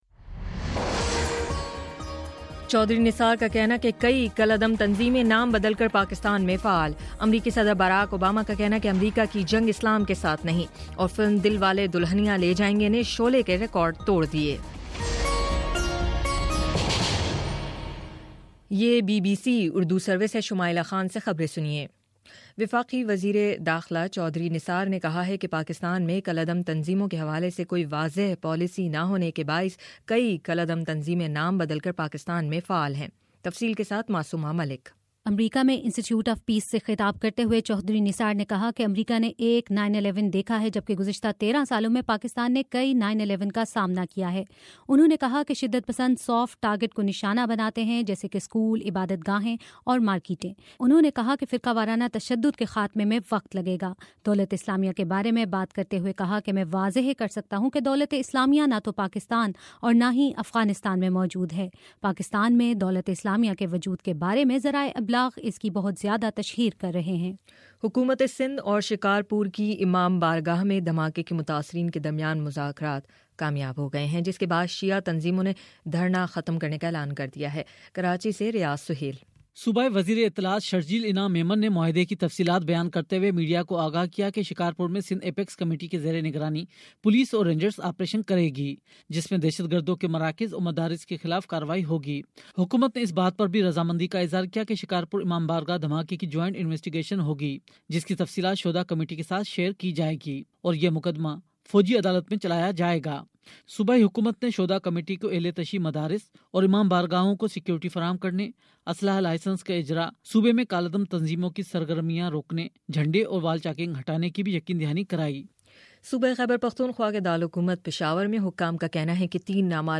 فروری19: شام سات بجے کا نیوز بُلیٹن